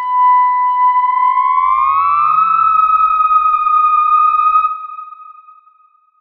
Theremin_Swoop_01.wav